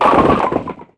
bowling09.mp3